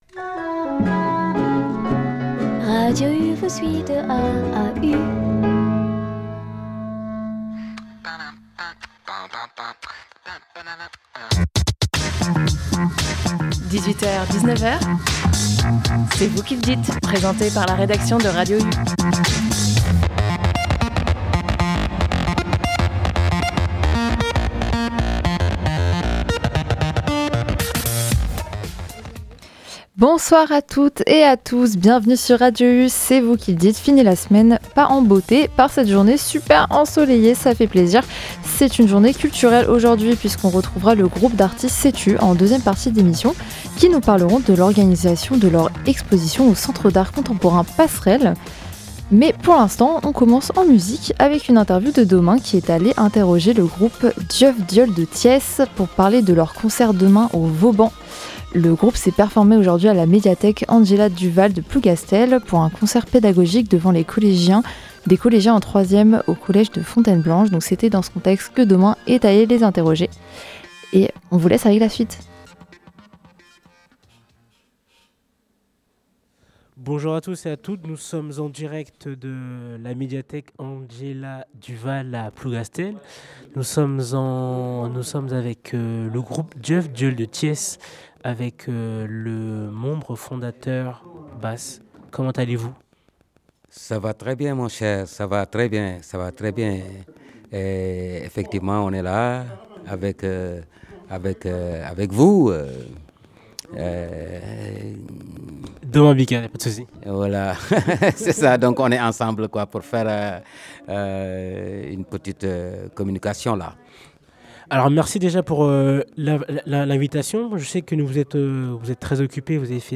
Nous avions commencé notre émission avec une interview du groupe Dieuf Dieul de Thiès après leur concert pédagogique à la médiathèque Angela Duval de Plougastel ;